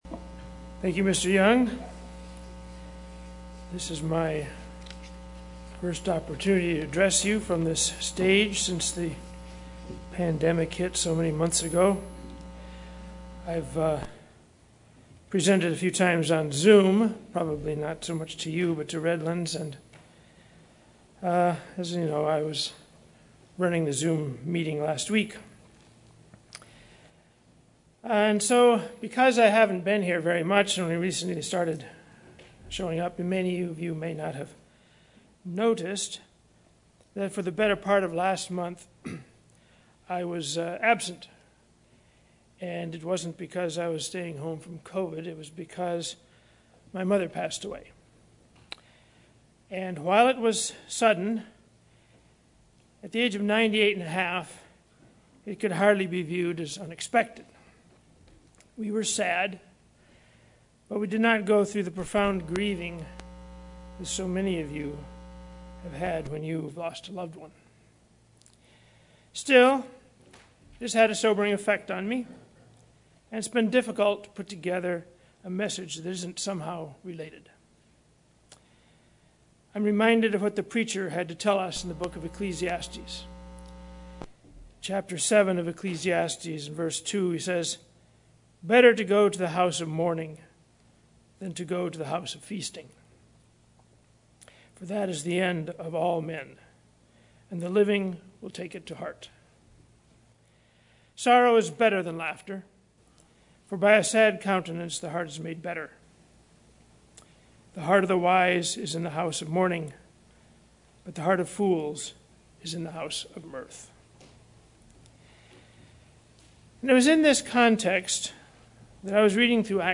Sermons
Given in San Diego, CA